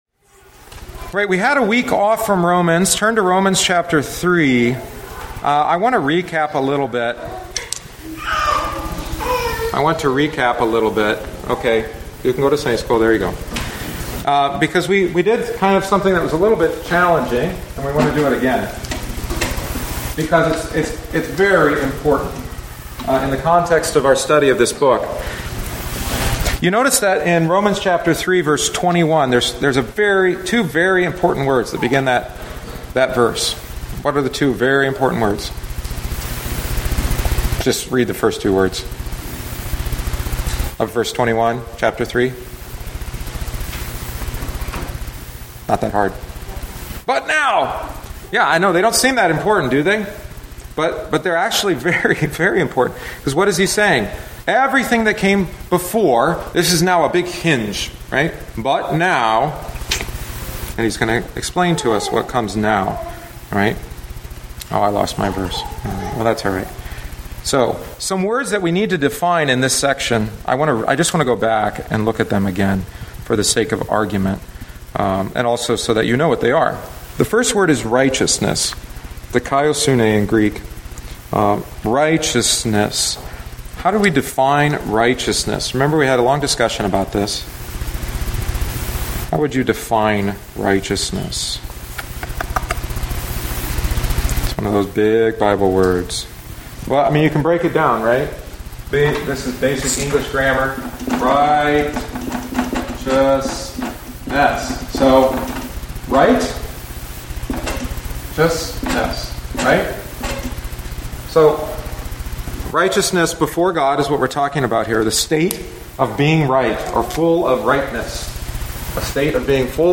The following is the ninth week’s lesson after a week off. We review the key terms of 3:21-25a and then cover 3:25b-31. God demonstrates is just by giving us salvation as a gift of faith.